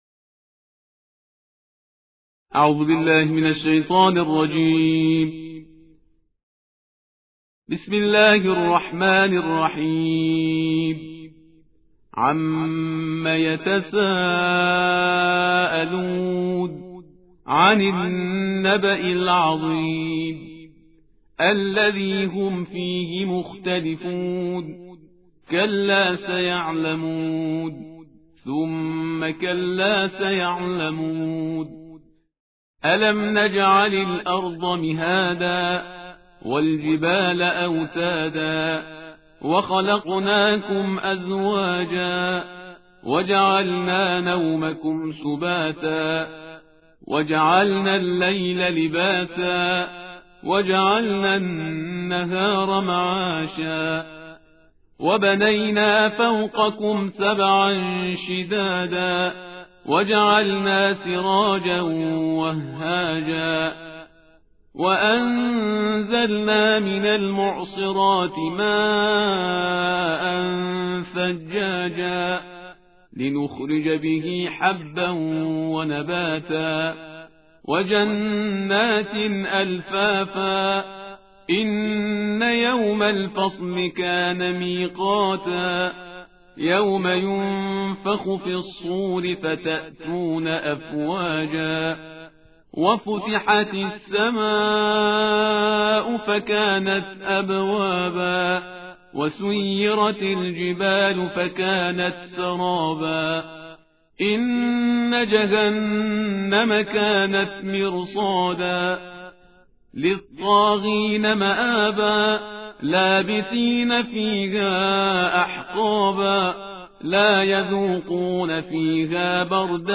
ترتیل جزءسی قرآن کریم